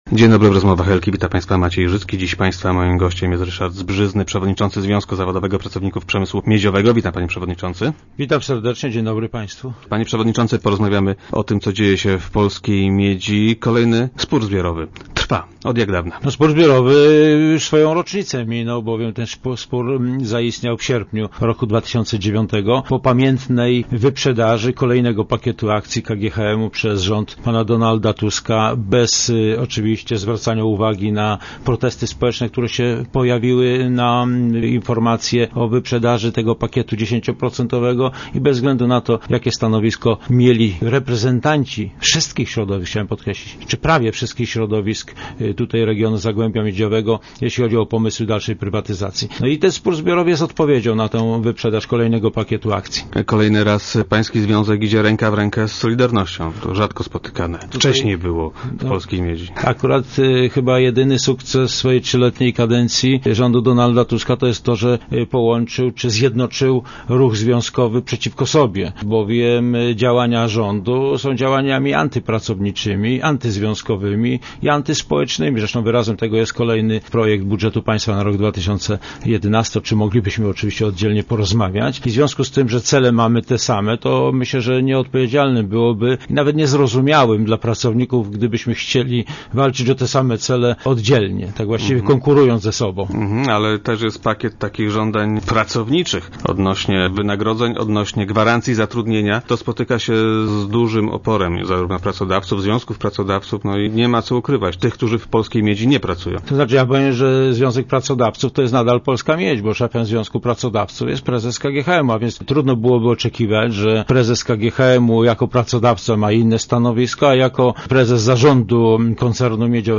Zbrzyzny był dziś gościem Rozmów Elki. Przypomniał na antenie, że spór w miedziowej spółce trwa niemal dokładnie rok.